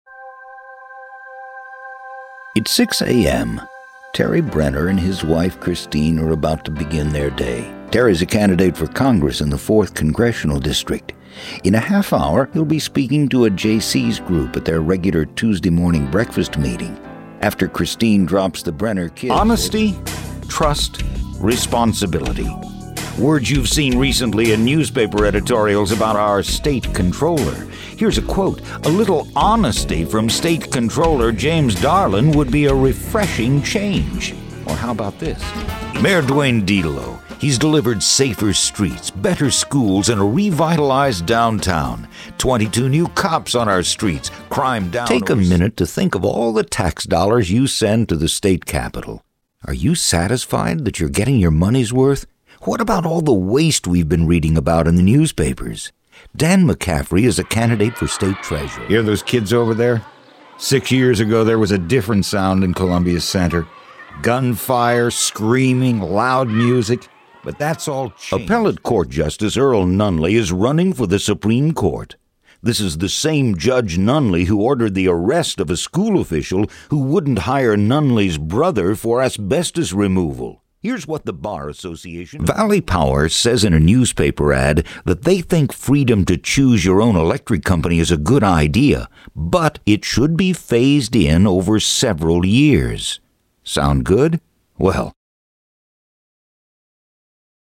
Voice Overs      Narrations      Commercials      Promos
Political Spots